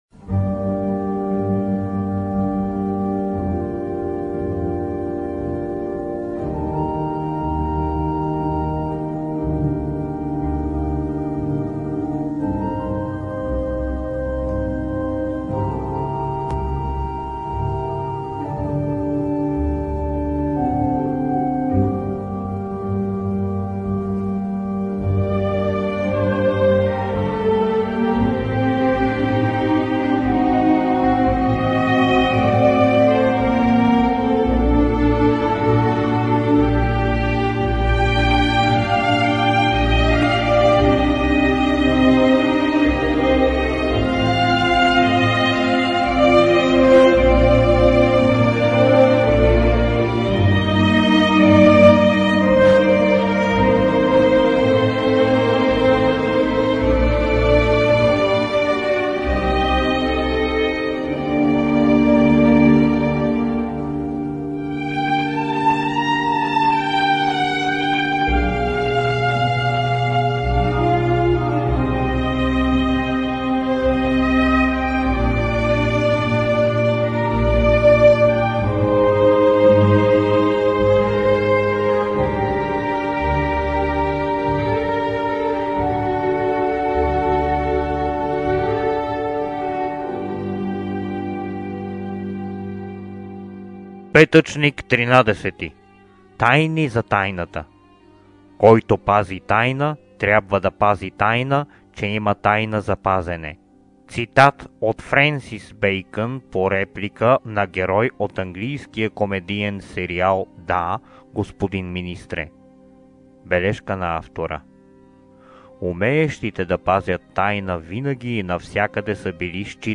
Това е мястото, където всеки петък, считано от 9 ноември 2018 г., ще бъде спущана по една философска миниатюра, записана на анонсирания по-долу музикален фон.
Миниатюрите ще записва съчинителят им, който: